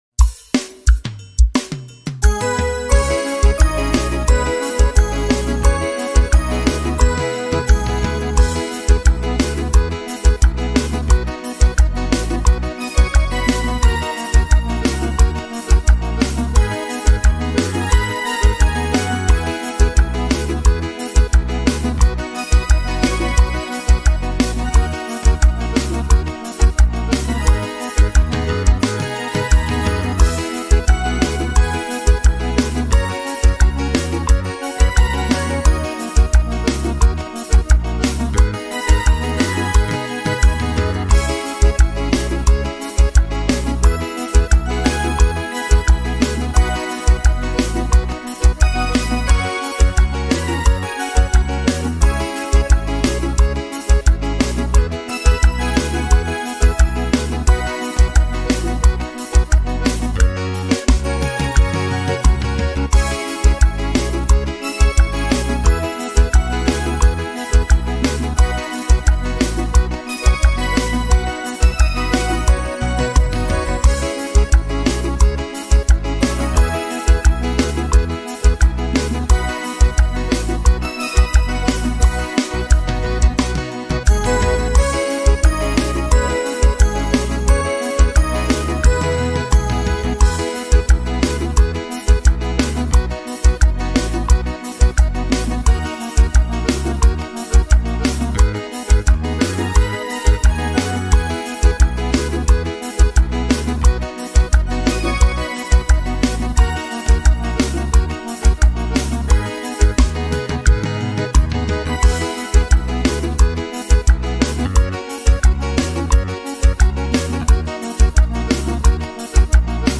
Ao vivo teclado.